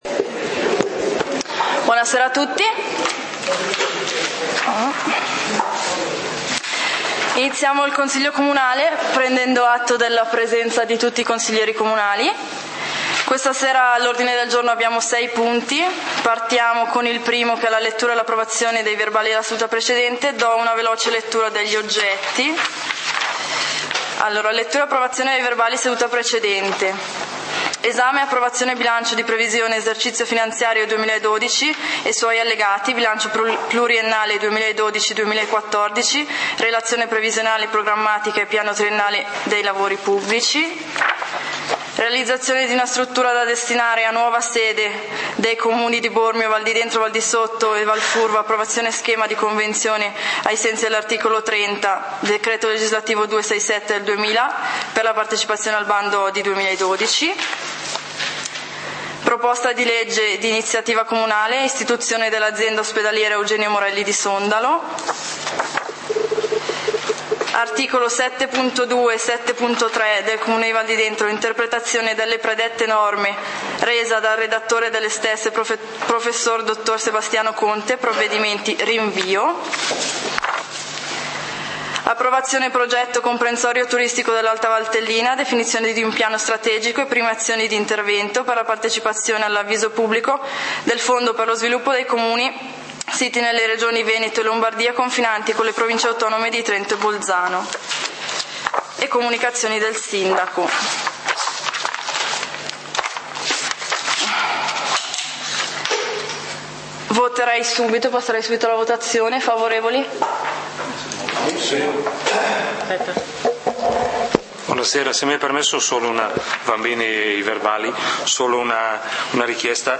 Punti del consiglio comunale di Valdidentro del 24 Agosto 2012